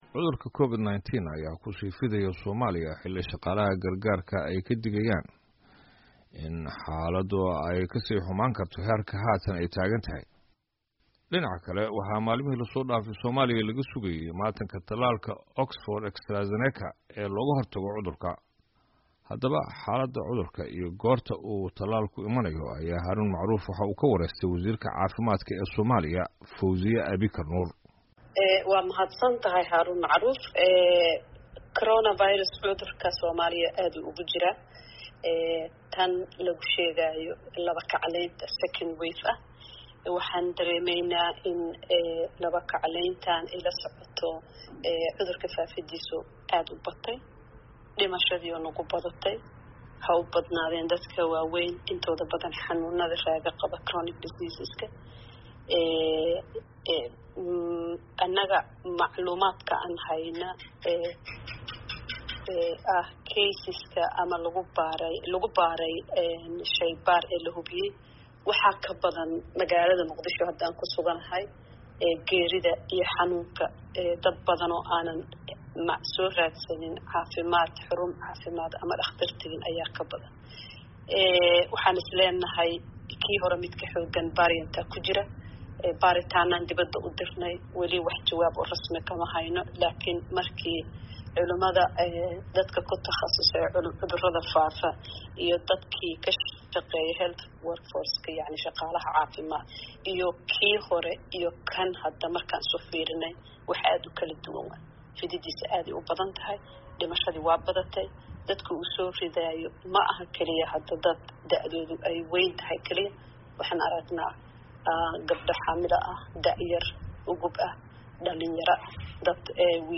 Wareysi: Wasiir Fowsiya oo ka hadashay xaaladda COVID-19 iyo tallaal la sugayo